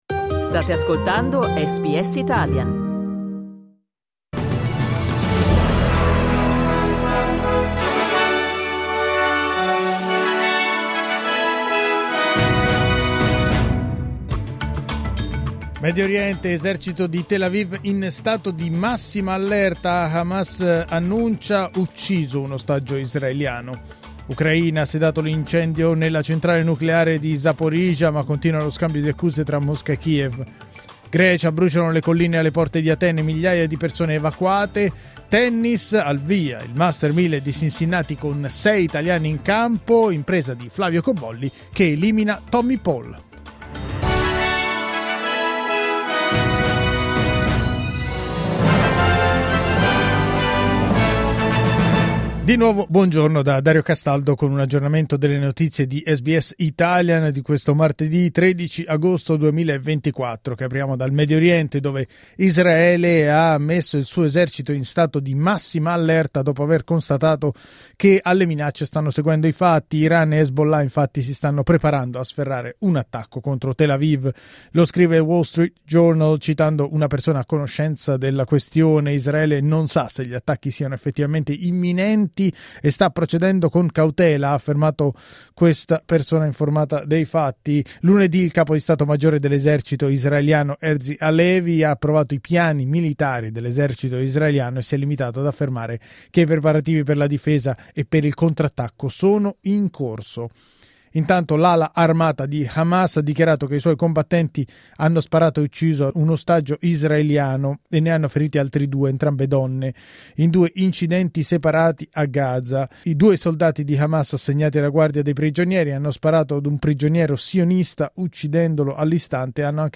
News flash martedì 13 agosto 2024